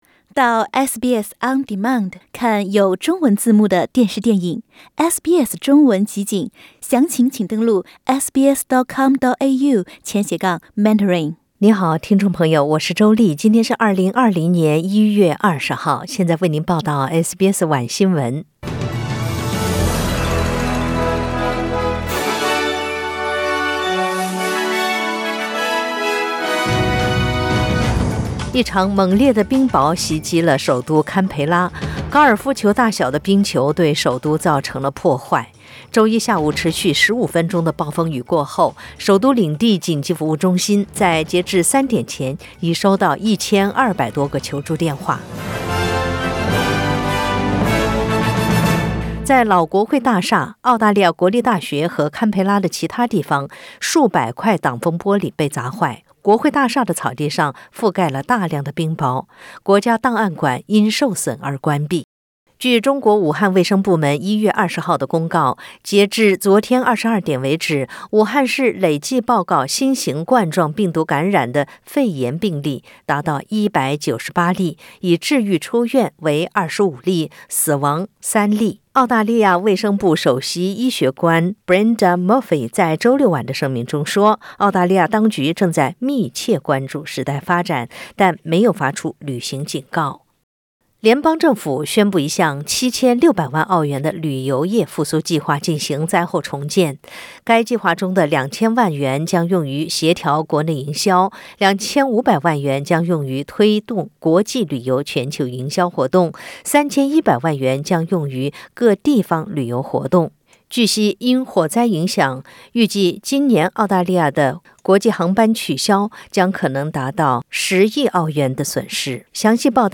SBS 晚新闻 （1月20日）